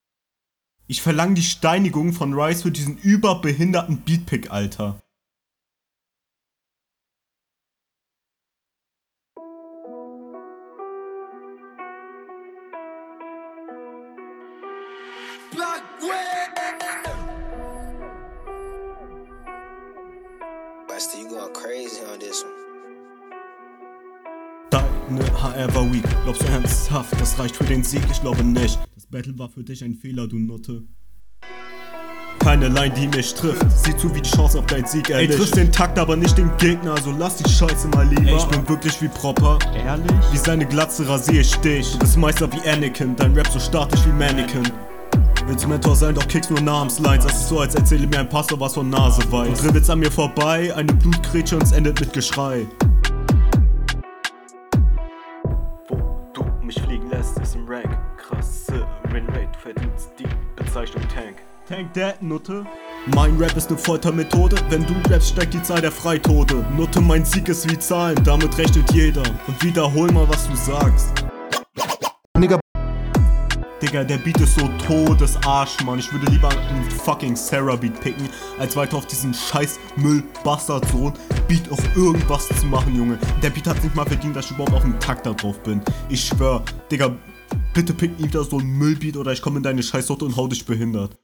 kommst leider nicht so gut auf den beat und sich drüber aufregen auch bisschen lost